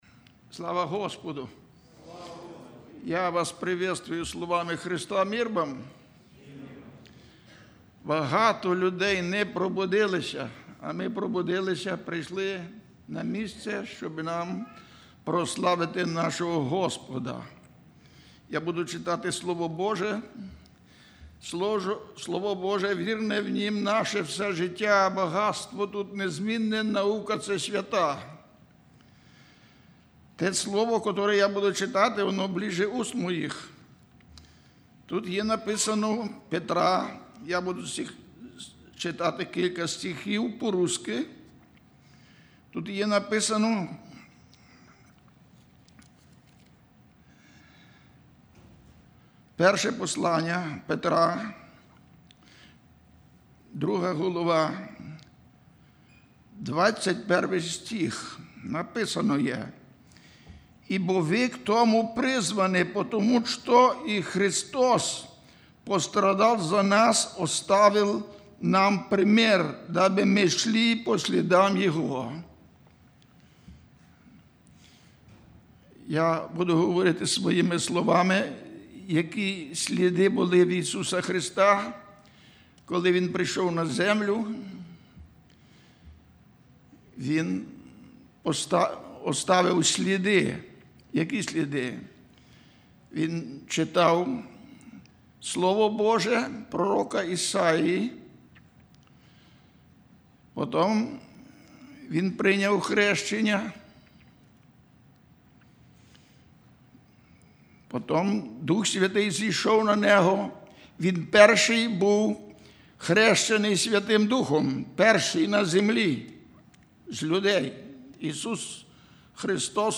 07+Проповедь.mp3